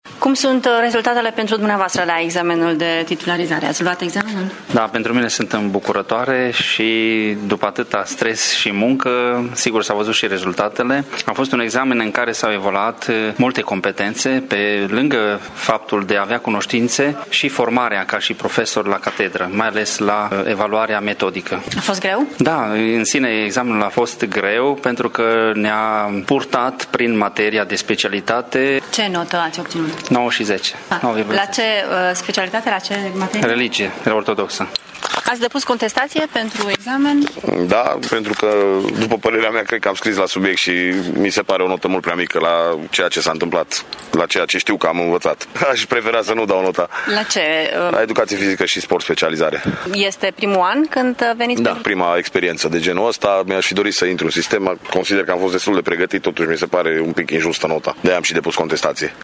Examenul a fost destul de dificil anul acesta, spun chiar și profesorii cu experiență: